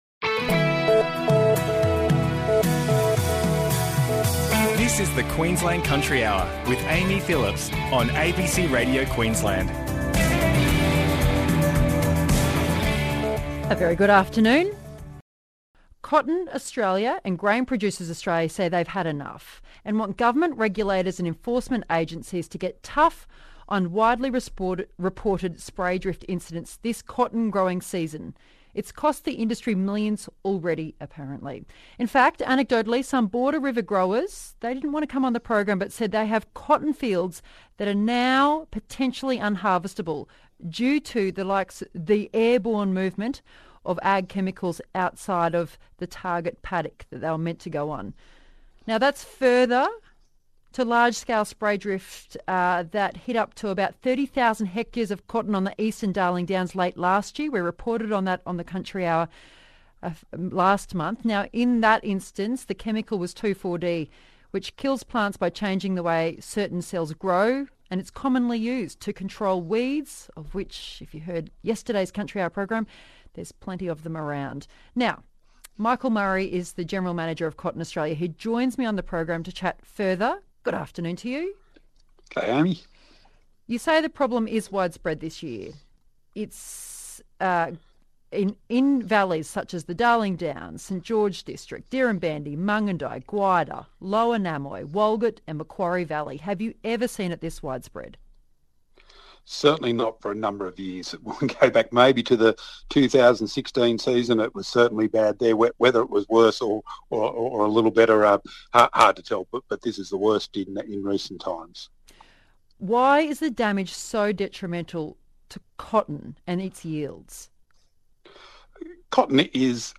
There's also discussion with growers about the world-first network of 100 weather stations from Central Queensland to the NSW-Victorian border currently being turned on to help growers detect inversion, which is the most likely the cause of off-target crop damage.